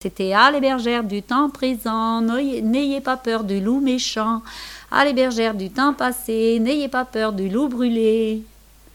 Enfantines diverses
chansons, comptines, contes
Pièce musicale inédite